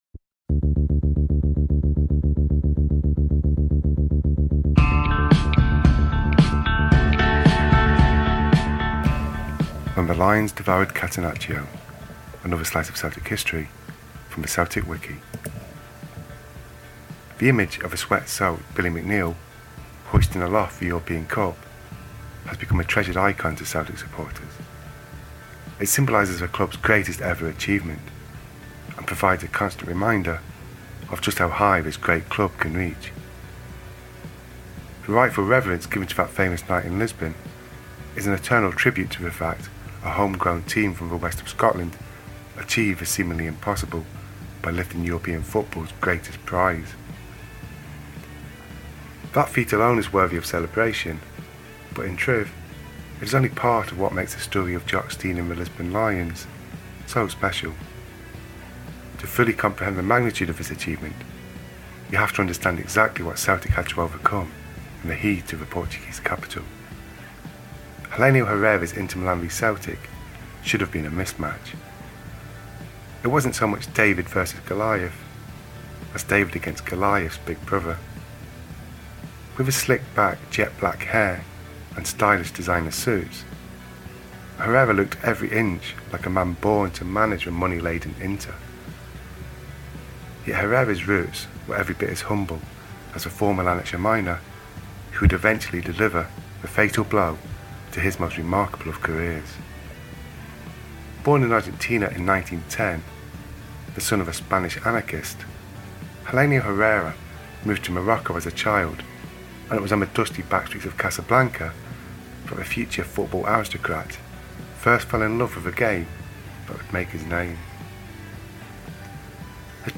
Tale from the Celtic Wiki plus the radio commentary of the goals